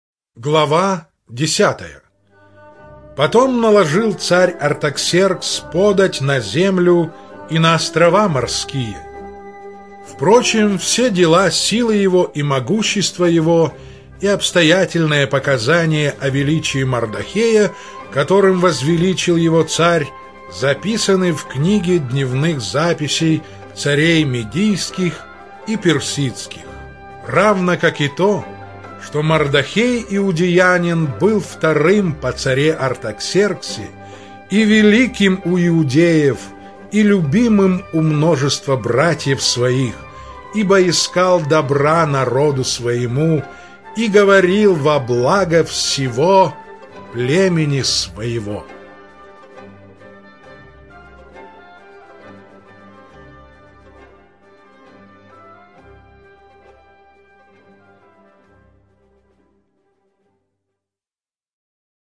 Чтение сопровождается оригинальной музыкой и стерео-эффектами